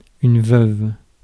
Ääntäminen
Synonyymit guillotine Ääntäminen France: IPA: [vœv] Haettu sana löytyi näillä lähdekielillä: ranska Käännös Substantiivit 1. viuda {f} Suku: f .